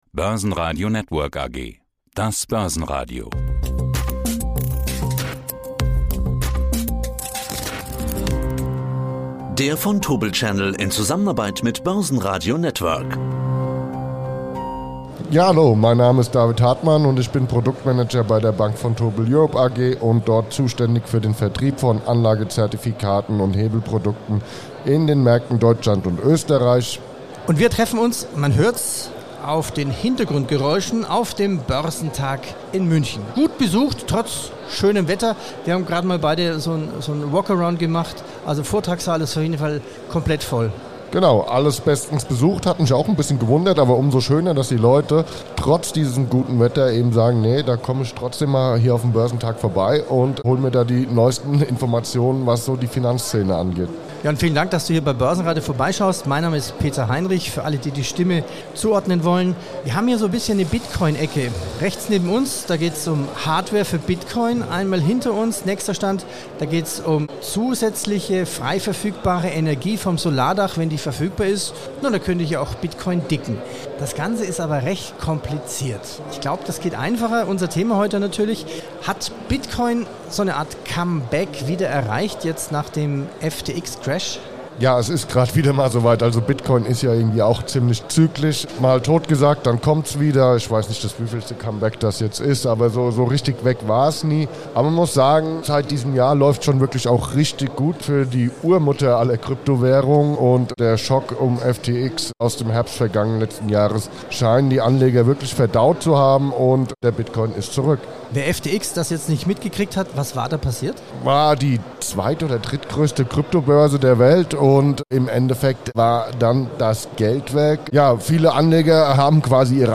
auf dem Börsentag München, "der Bitcoin ist ziemlich zyklisch."